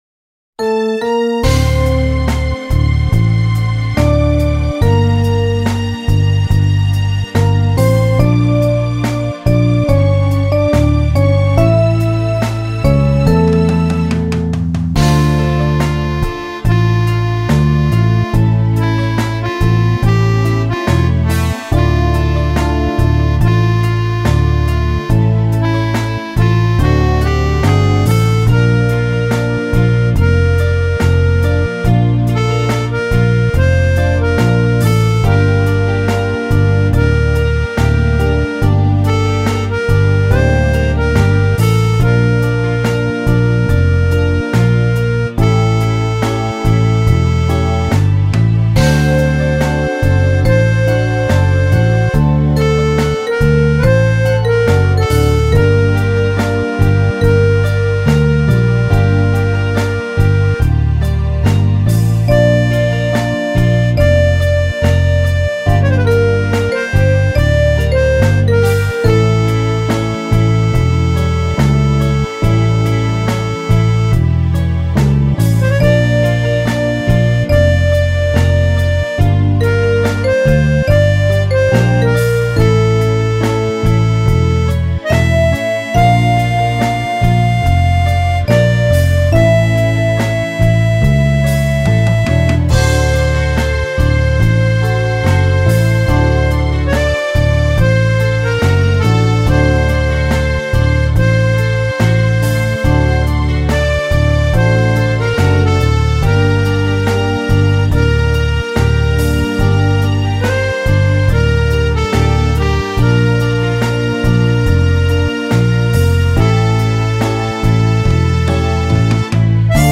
2978   05:51:00   Faixa: 5    Clássica